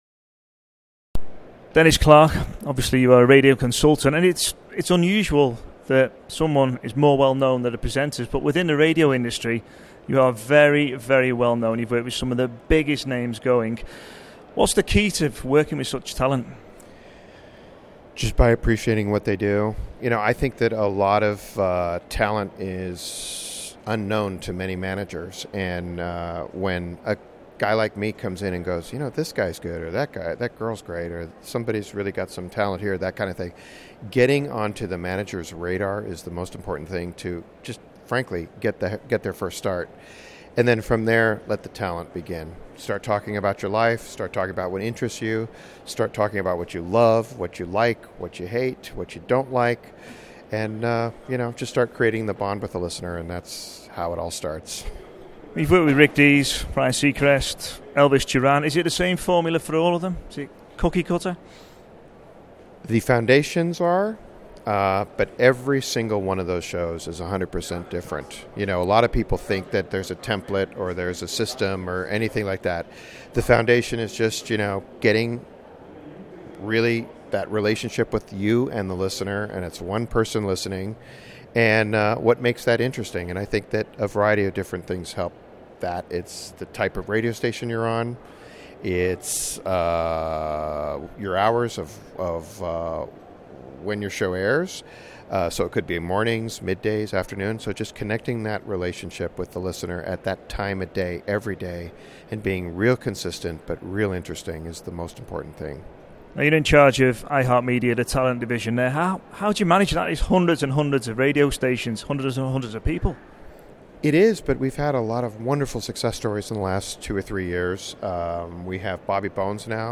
RadioToday Live Interviews